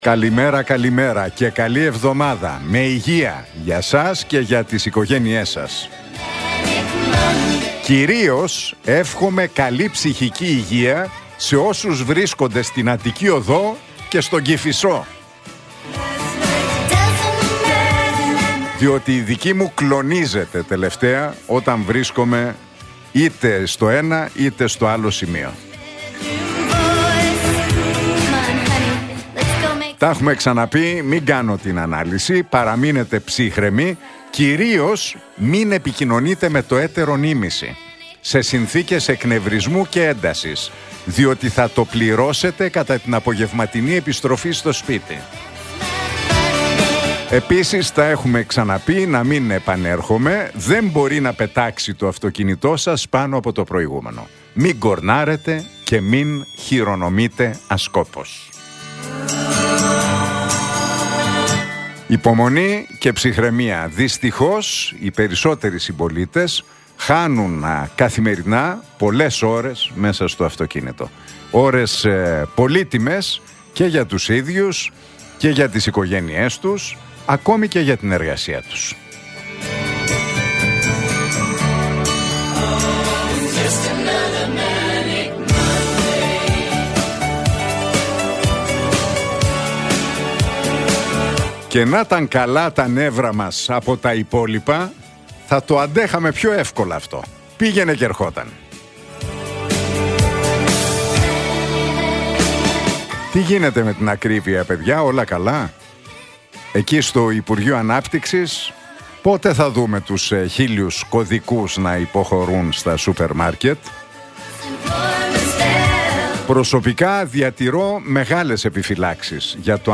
Ακούστε το σχόλιο του Νίκου Χατζηνικολάου στον ραδιοφωνικό σταθμό Realfm 97,8, την Δεύτερα 6 Οκτώβριου 2025.